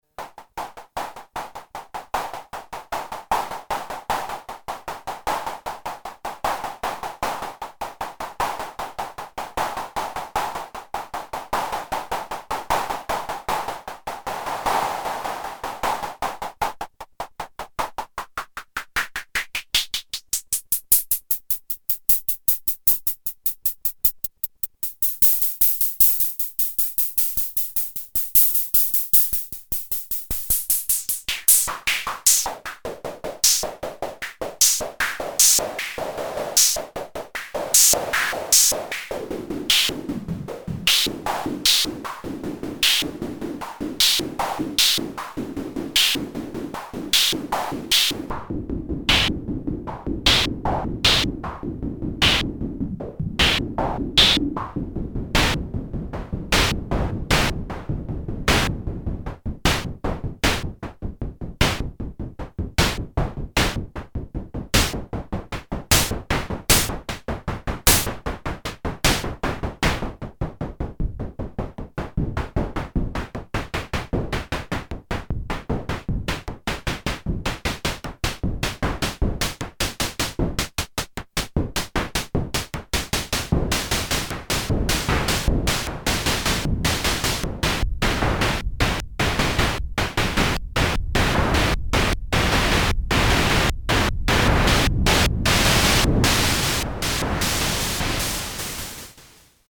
1. Peak & Hold is controlling the Noise VCA
dynamic envelope shape, while the
Drum machine